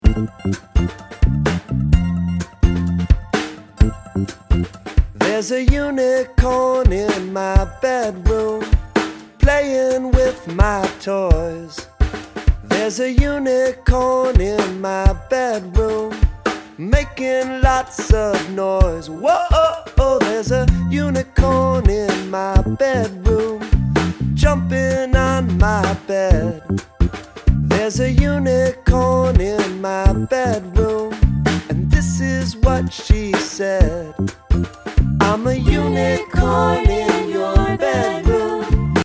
Indie/Rock